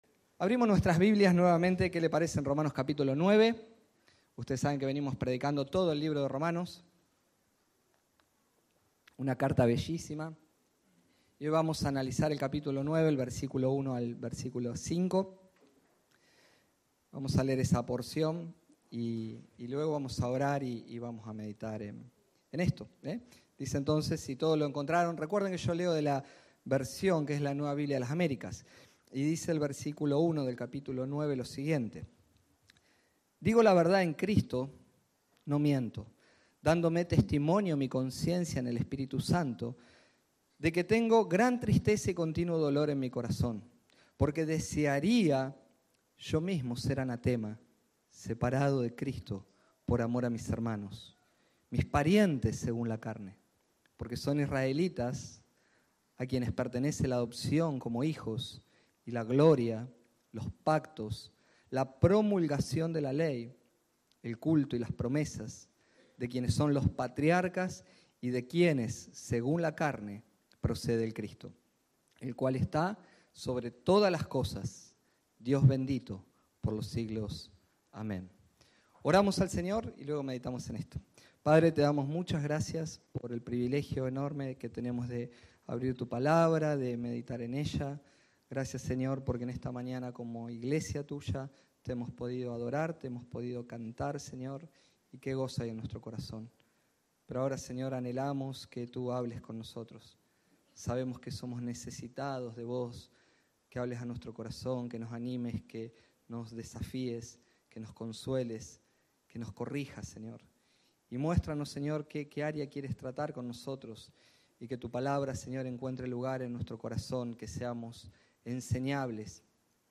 Este sermón